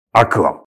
アクアコイン決済音にご協力いただきました
電子地域通貨「アクアコイン」の運用開始5周年を記念し、アクアコイン決済音5周年記念特別バーションにご協力いただきました。
特別決済音「アクア」 (音声ファイル: 27.5KB)